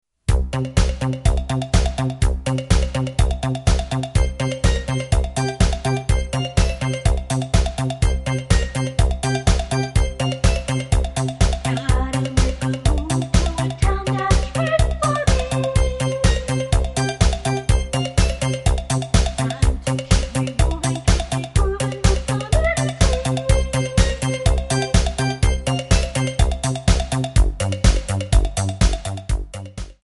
Backing track files: Disco (180)